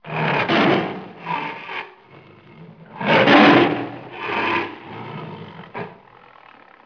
دانلود آهنگ حیوانات جنگلی 26 از افکت صوتی انسان و موجودات زنده
جلوه های صوتی
دانلود صدای حیوانات جنگلی 26 از ساعد نیوز با لینک مستقیم و کیفیت بالا